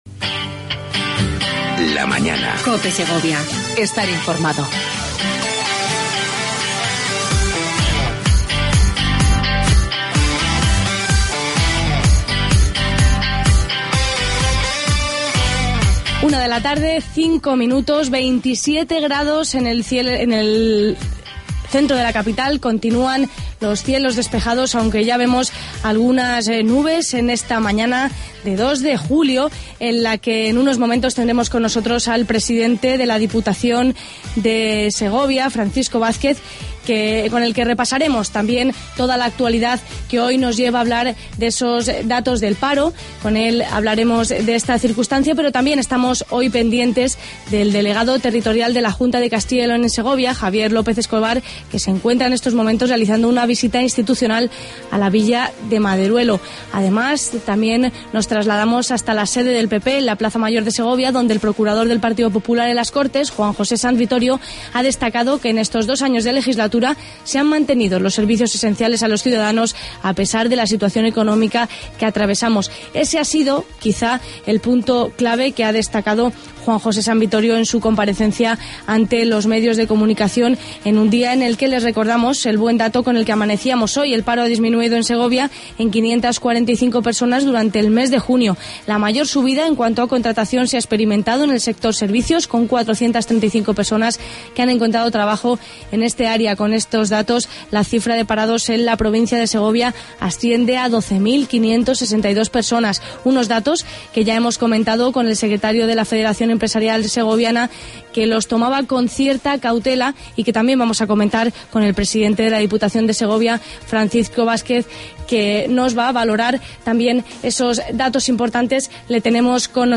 AUDIO: Entrevista con Francisco Vazquez, Presidente de la Diputación de Segovia.